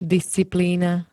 disciplína [d-] -ny -lín ž.
Zvukové nahrávky niektorých slov